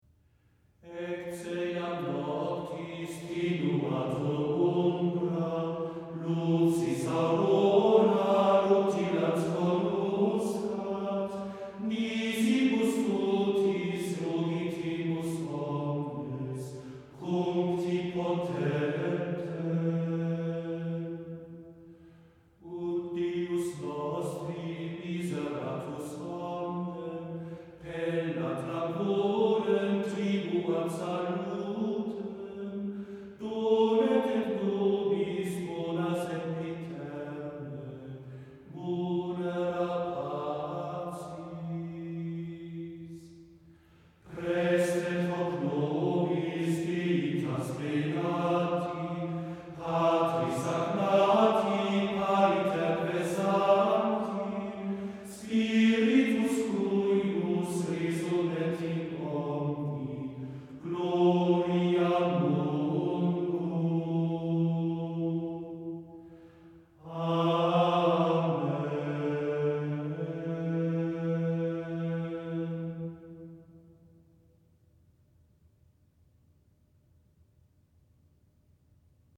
Gregoriana